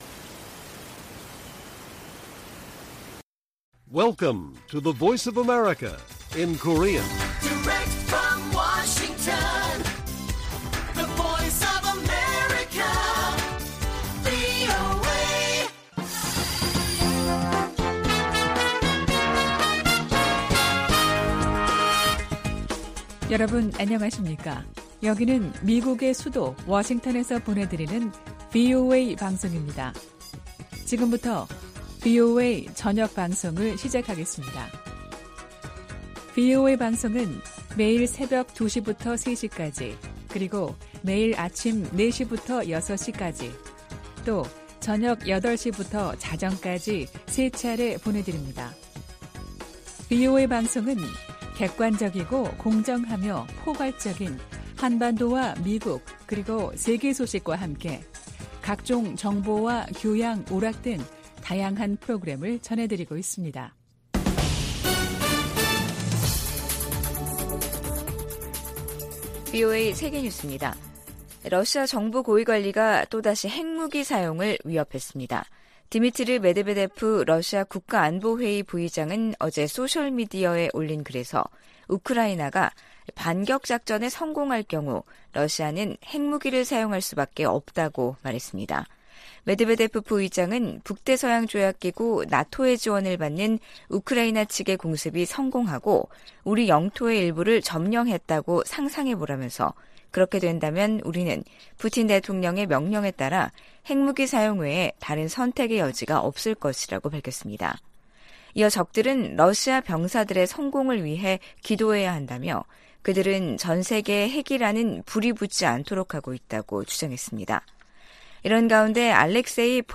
VOA 한국어 간판 뉴스 프로그램 '뉴스 투데이', 2023년 7월 31일 1부 방송입니다. 백악관이 미한일 3국 정상회담 개최를 공식 발표하며 북한 위협 대응 등 협력 확대 방안을 논의할 것이라고 밝혔습니다.